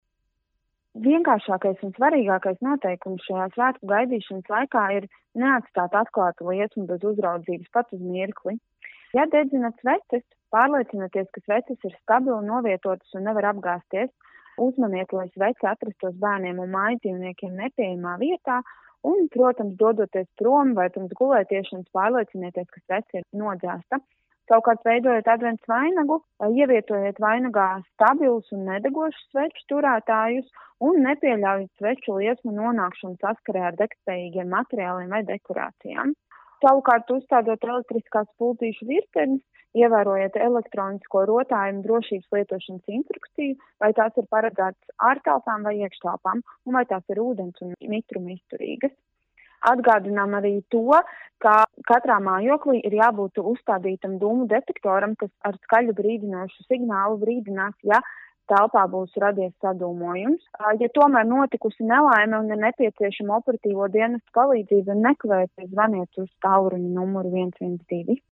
RADIO SKONTO Ziņās daži ieteikumi mierīgam Ziemassvētku gaidīšanas laikam